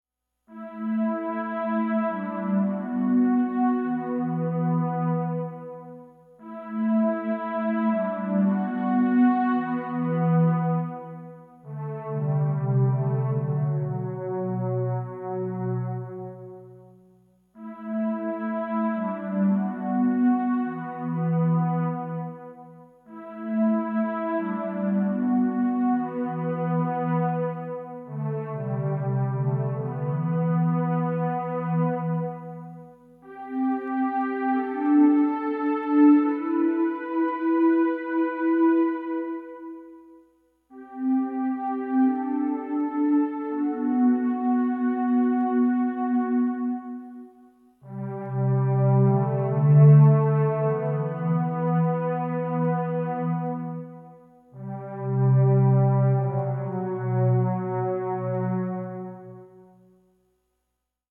Royalty free quiet peaceful music